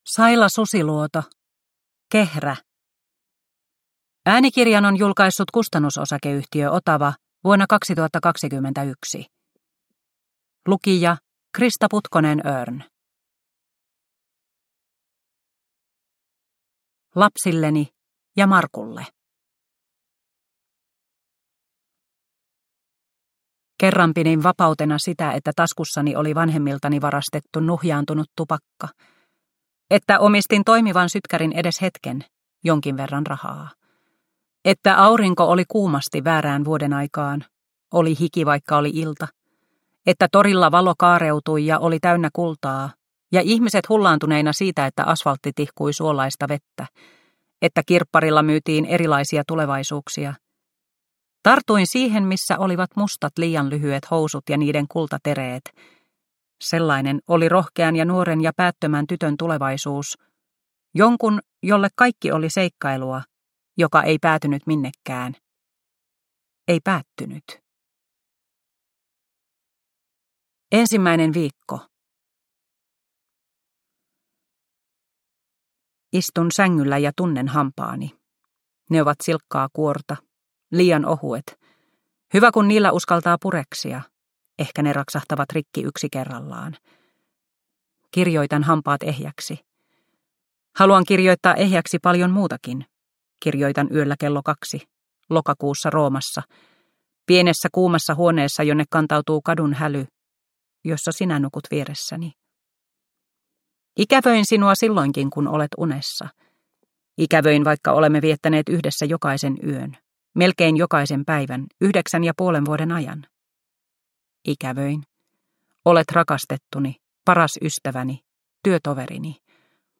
Kehrä – Ljudbok – Laddas ner